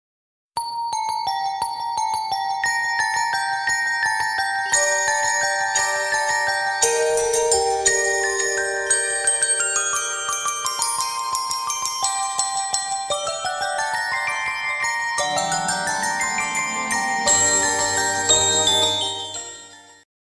bells.mp3